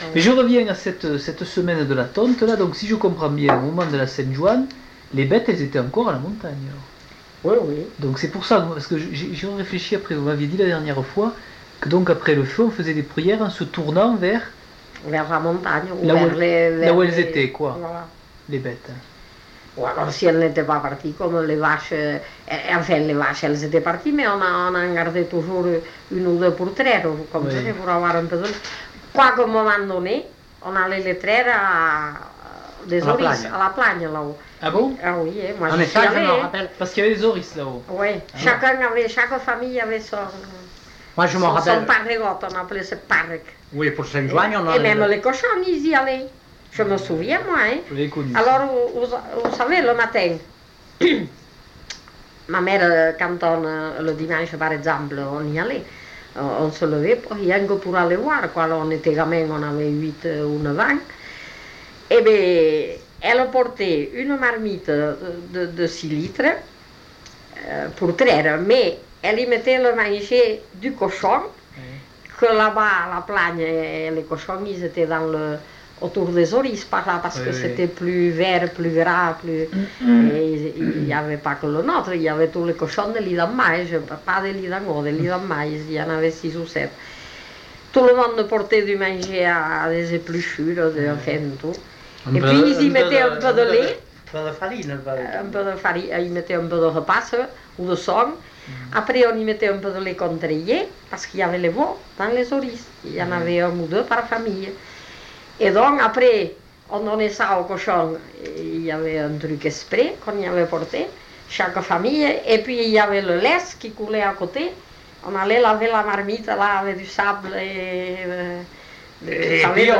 Aire culturelle : Couserans
Lieu : Eylie (lieu-dit)
Genre : témoignage thématique